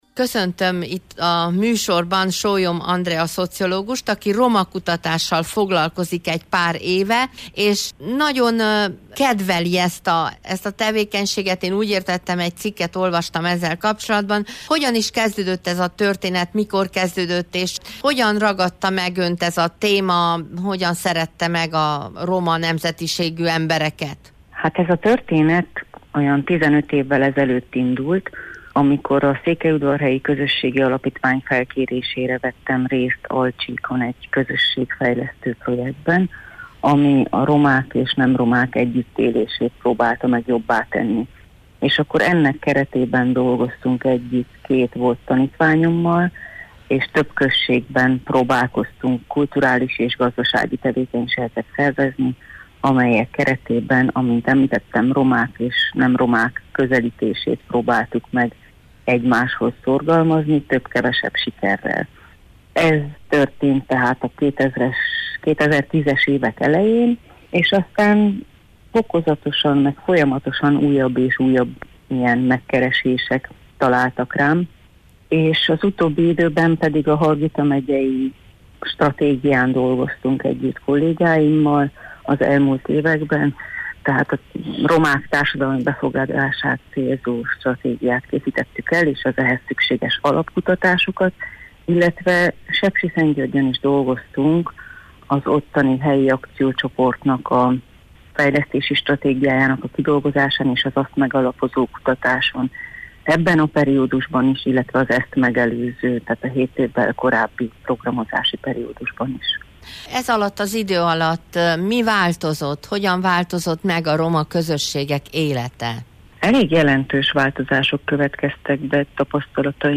A portréinterjú során beszéltünk arról is, hogy mit lehetne tenni a jobb egyetértés érdekében, hogyan közeledhetnének jobban a romák és nem romák, és mit ne tegyünk azért, hogy ez a kapcsolat megromoljon. https